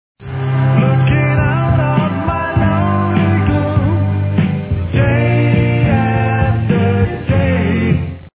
slide guitar
piano